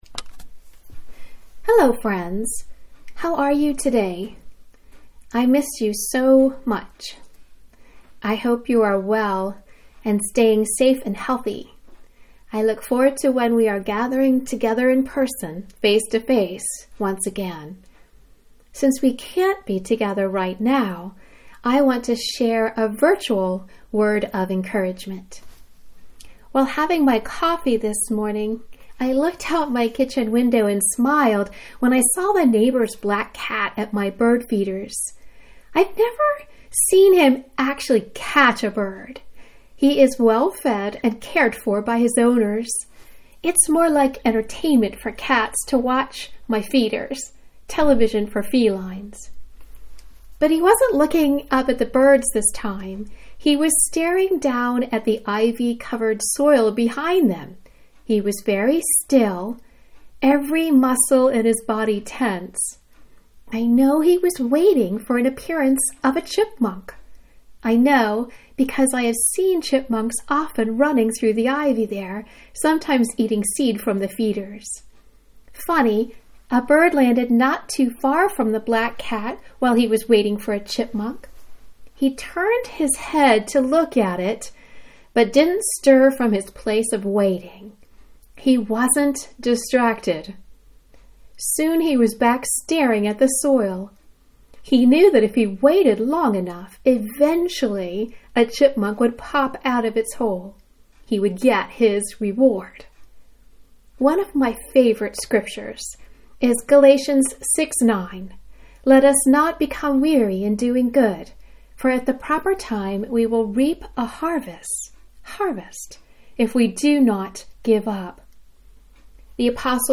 Meditation on Luke 24:13-35